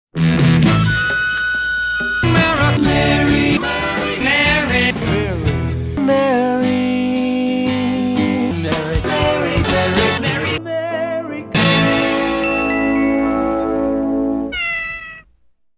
It’s hard to tell where one song starts and another ends.